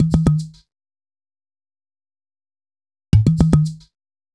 WVD BOTTLE.wav